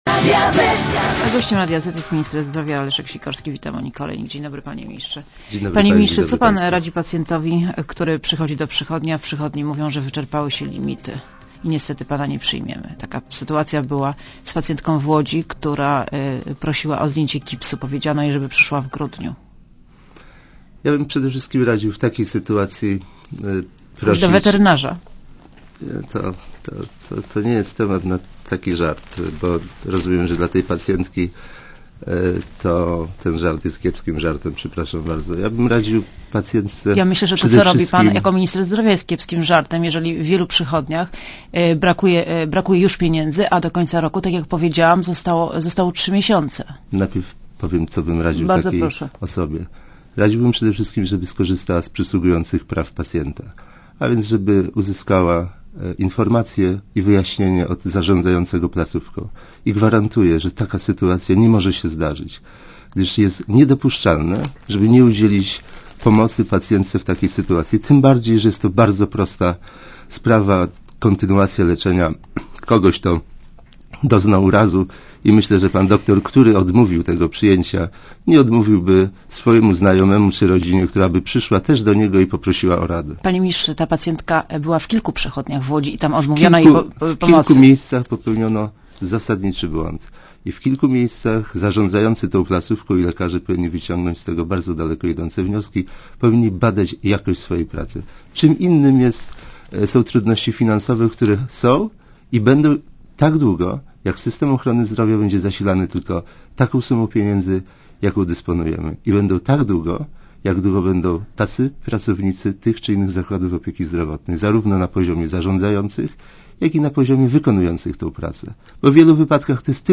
Gościem Radia Zet jest minister zdrowia Leszek Sikorski.
© (RadioZet) Posłuchaj wywiadu Gościem Radia Zet jest minister zdrowia Leszek Sikorski.